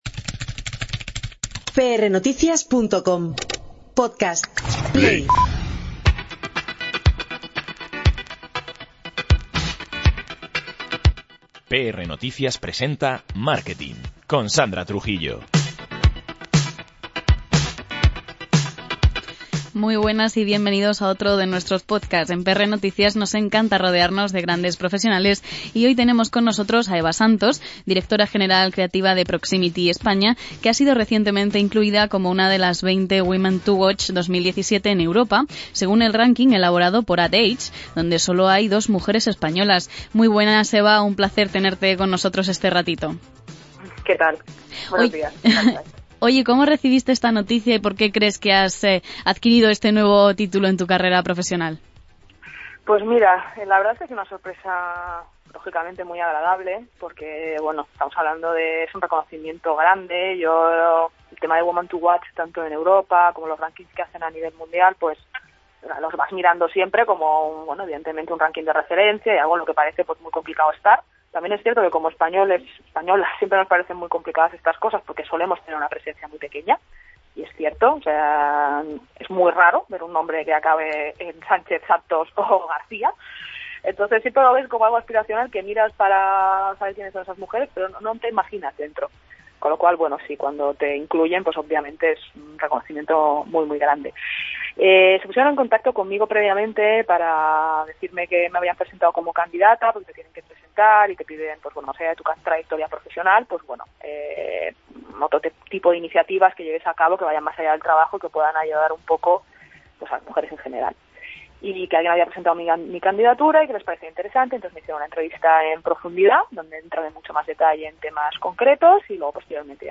No te pierdas esta entrevista en podcast.